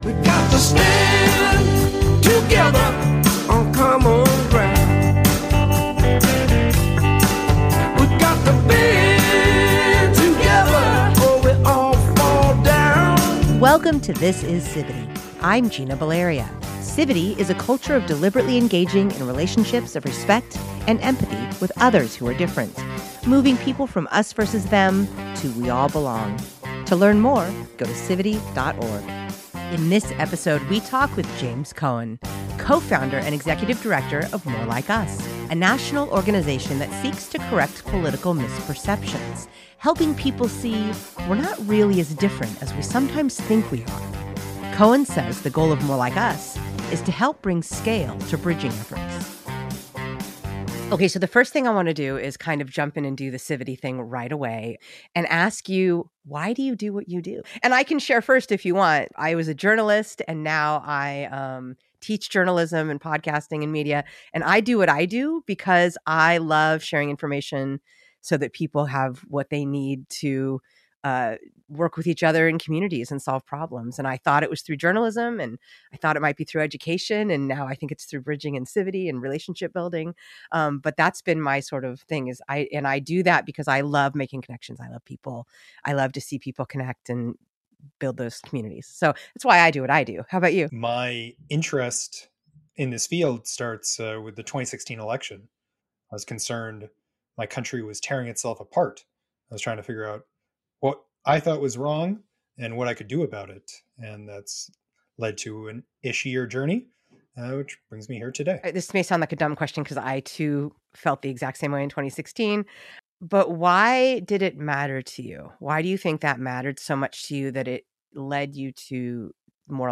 Our podcast showcases interviews with people bridging power-based divides to move communities forward on